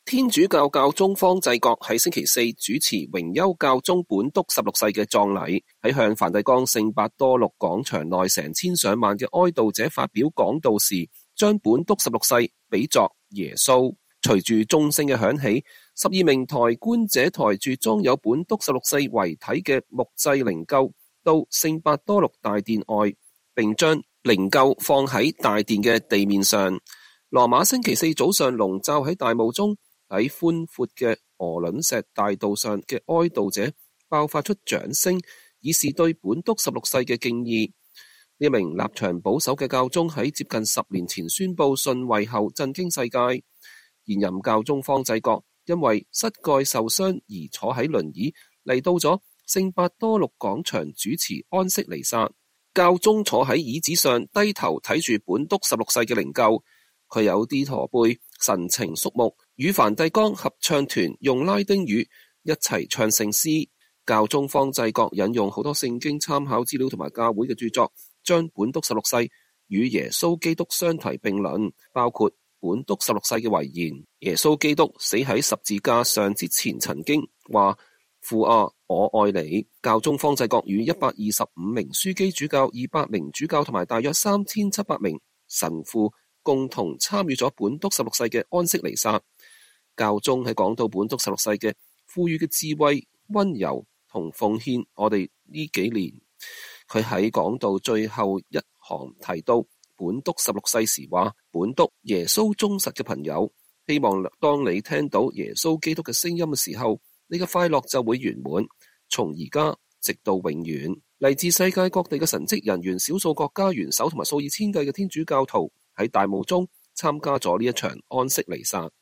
天主教教宗方濟各在星期四主持榮休教宗本篤十六世的葬禮，在向梵蒂岡聖伯多祿廣場内成千上萬的哀悼者發表了講道時將本篤十六世比作耶穌。